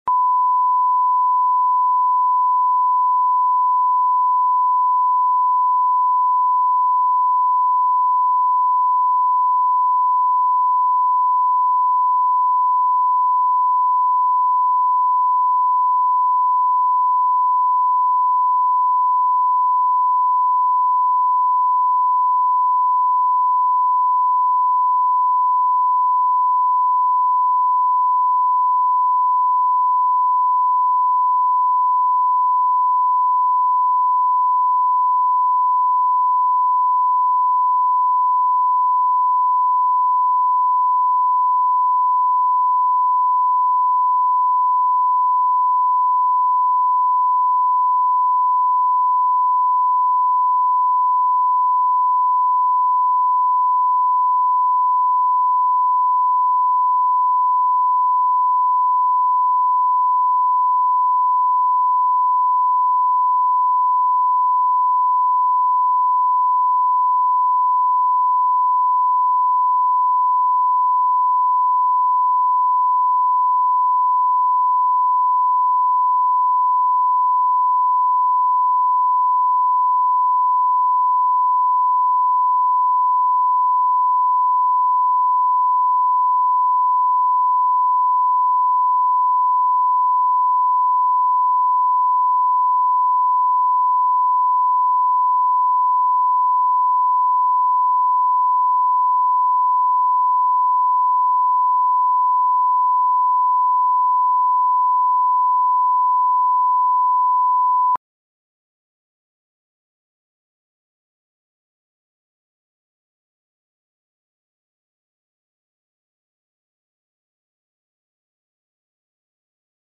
Аудиокнига Ревнует- значит любит | Библиотека аудиокниг